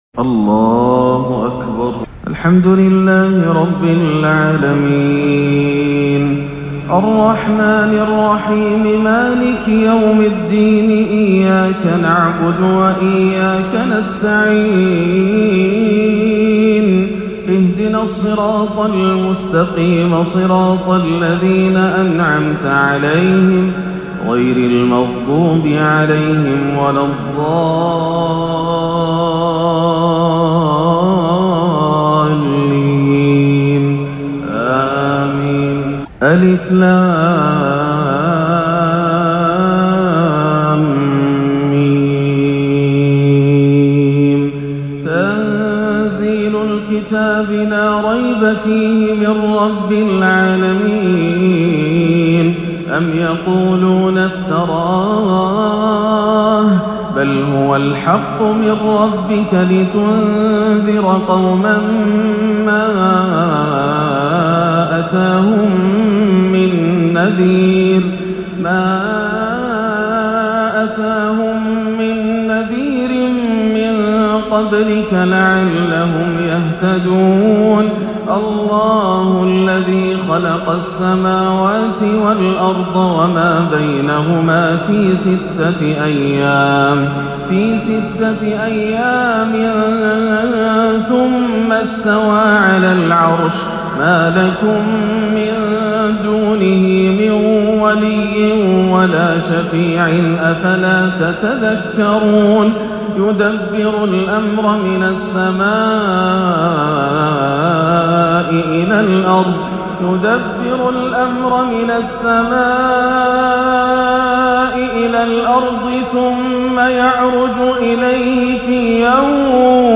(وما تشاءون إلا أن يشاء الله) بأجمل وأخشع الأداءات يرتل شيخنا سورتي السجدة والإنسان - الجمعة 14-11 > عام 1439 > الفروض - تلاوات ياسر الدوسري